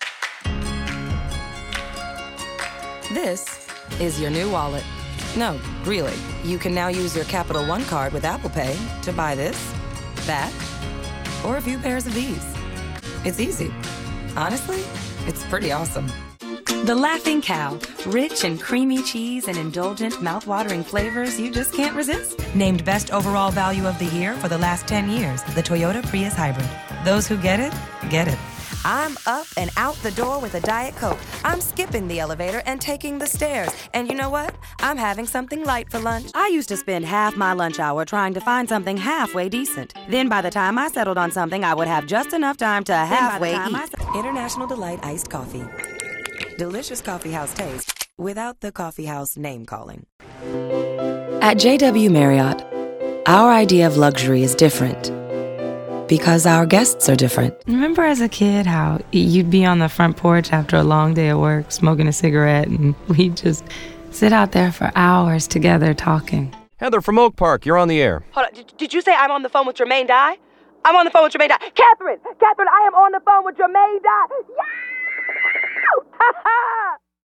Voiceover : Commercial : Women